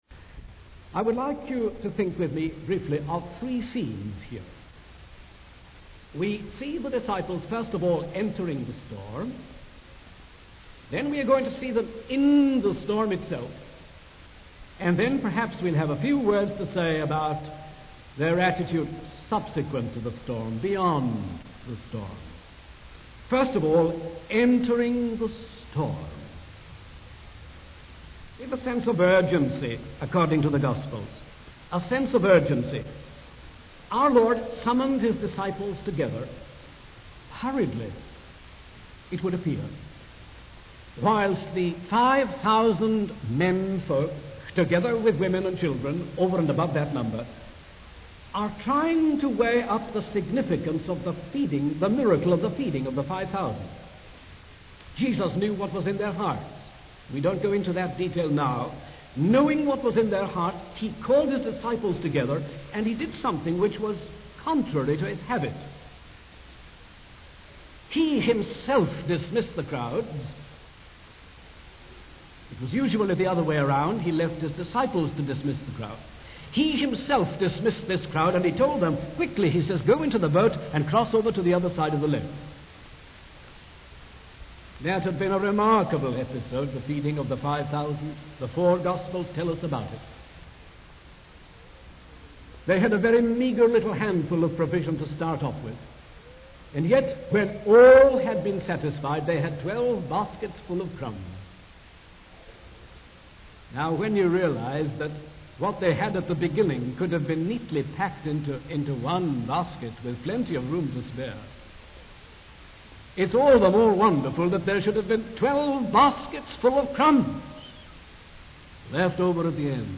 In this sermon, the preacher emphasizes the importance of learning lessons in the midst of storms and challenges. He describes a scene where Jesus is watching over his disciples in a boat during a storm, involving his heavenly father in their progress. The preacher highlights the significance of Jesus waiting to intervene in the storm, explaining that he permits storms in order to use them for a higher purpose.